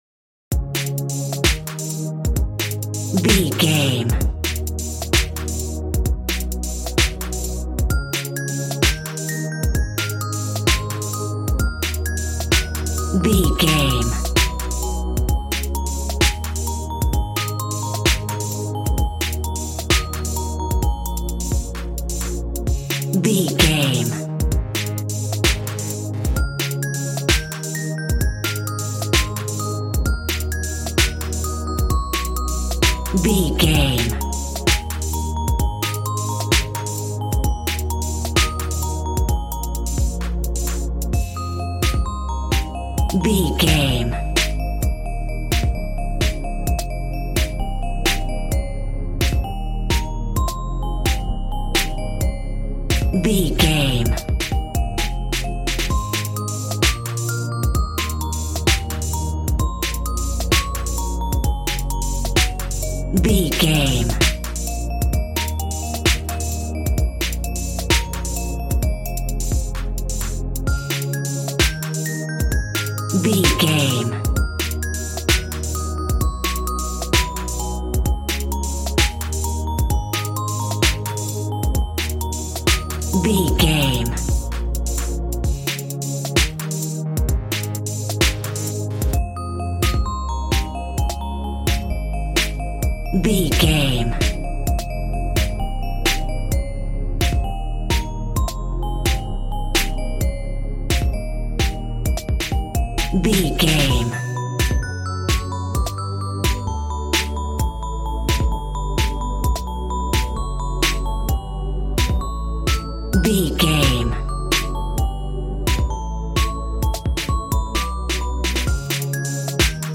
Aeolian/Minor
A♭
groovy
synthesiser
drums
piano